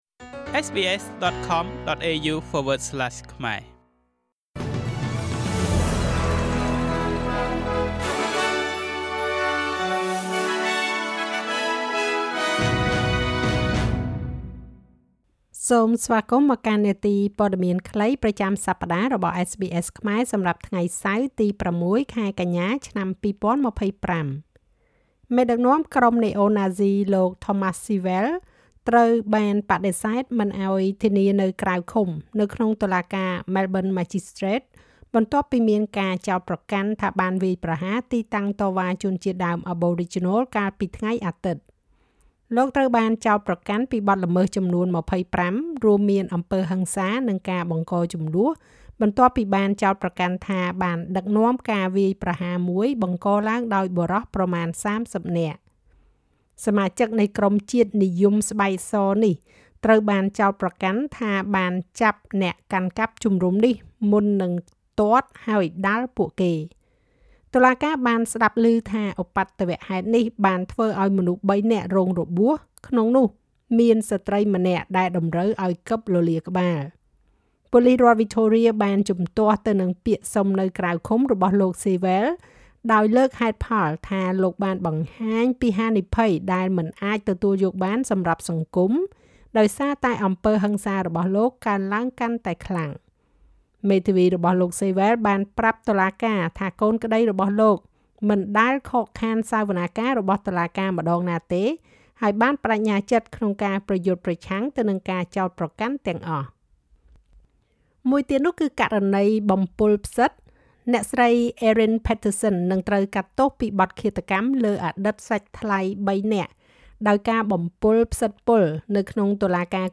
នាទីព័ត៌មានខ្លីប្រចាំសប្តាហ៍របស់SBSខ្មែរ សម្រាប់ថ្ងៃសៅរ៍ ទី៦ ខែកញ្ញា ឆ្នាំ២០២៥